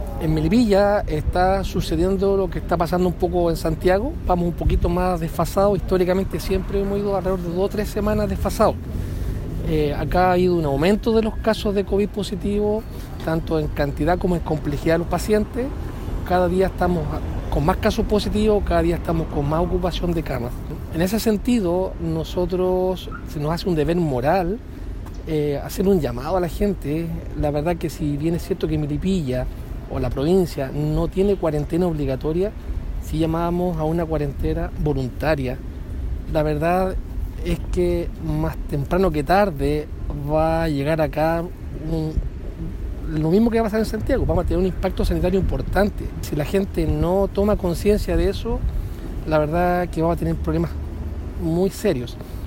Por medio de una conferencia de prensa